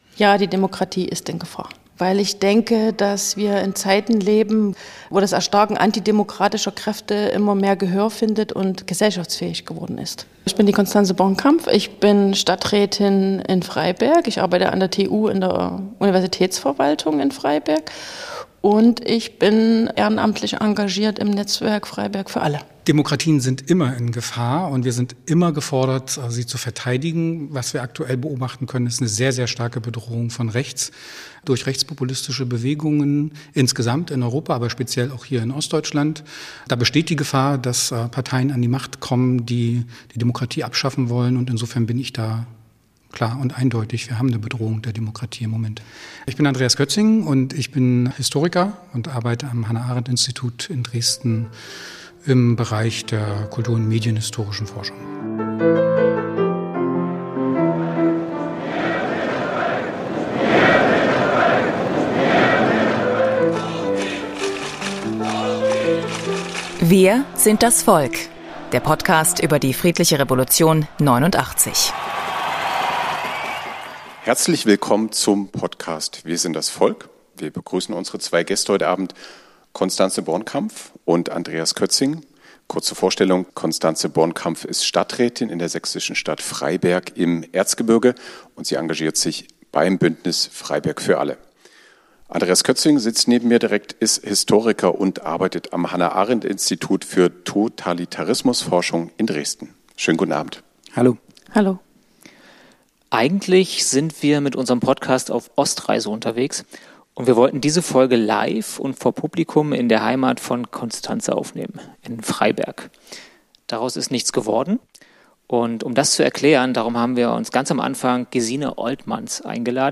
Es fand in den Räumen der Stiftung Friedliche Revolution in Leipzig statt - leider ohne Publikum.